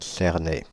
Cernay (French pronunciation: [sɛʁnɛ]
Fr-Paris--Cernay.ogg.mp3